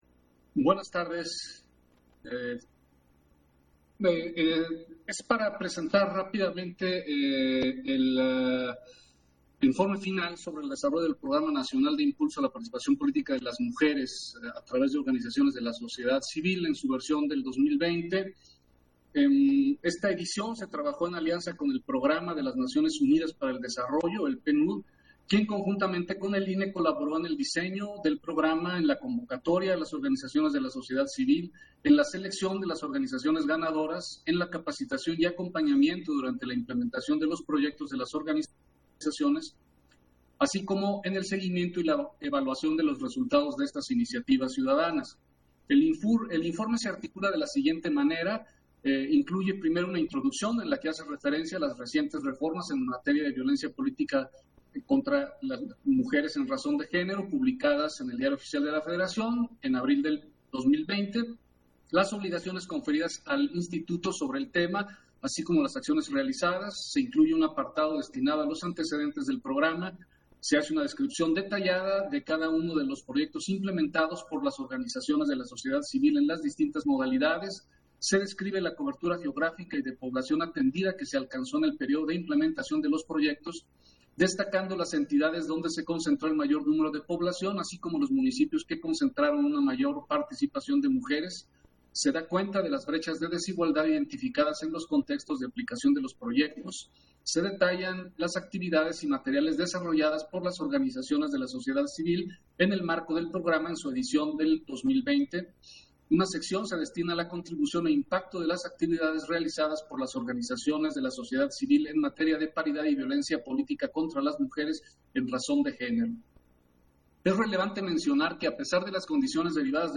Intervención de Martín Faz, en Sesión Extraordinaria, en el informe final sobre el desarrollo del Programa Nacional de Impulso a la Participación Política de las Mujeres a través de organizaciones de la sociedad civil 2020